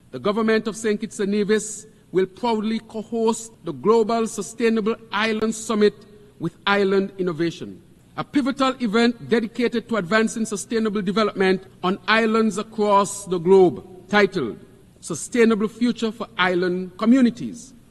This was Prime Minister-Dr. Terrance Drew during the UN General Assembly as he provided information on the summit: